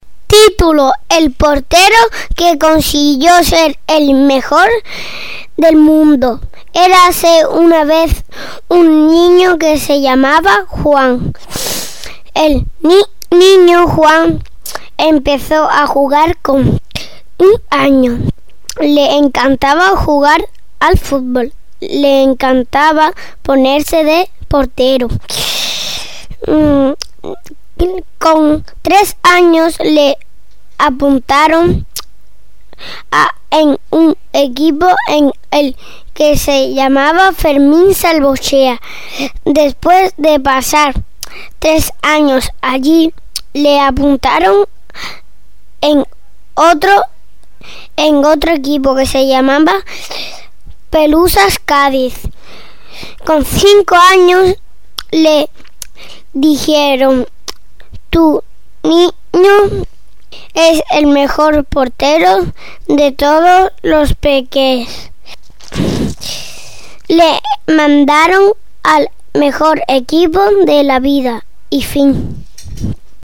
cuento